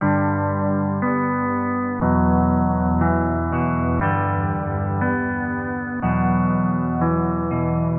描述：低沉的弦乐吉他
Tag: 120 bpm Trap Loops Guitar Electric Loops 1.35 MB wav Key : Em FL Studio